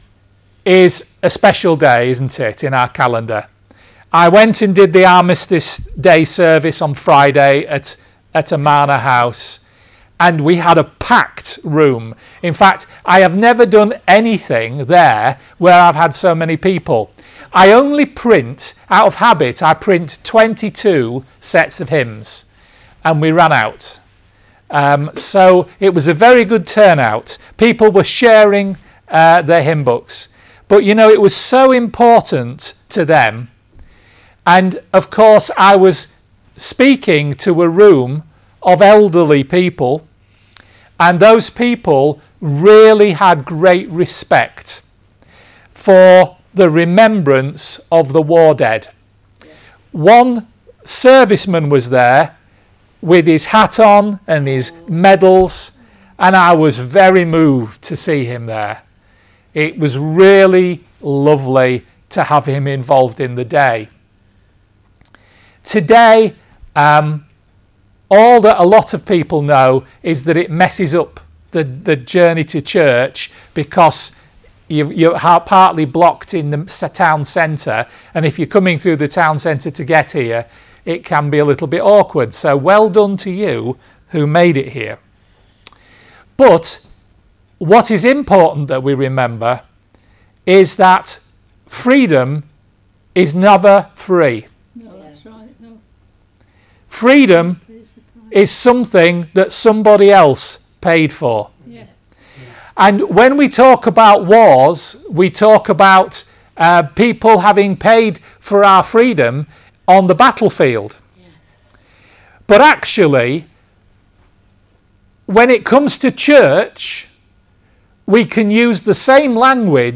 A special talk for remembrance Sunday